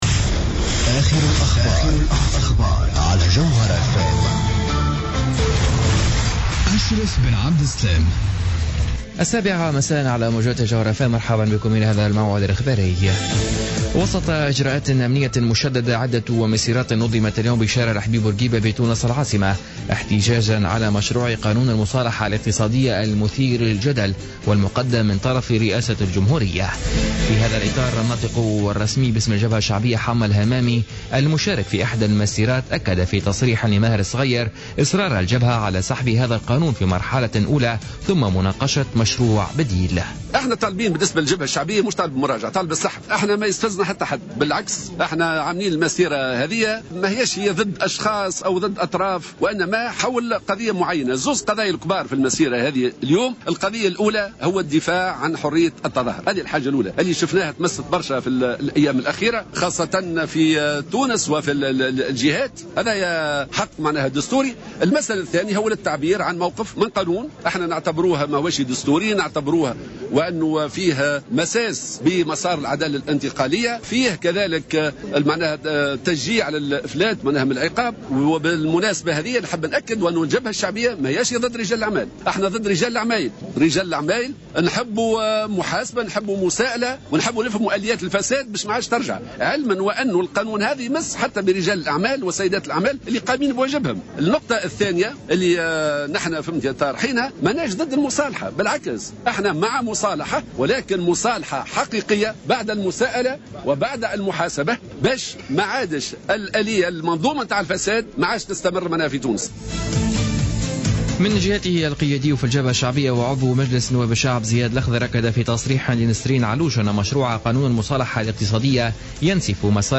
نشرة أخبار السابعة مساء ليوم السبت 12 سبتمبر 2015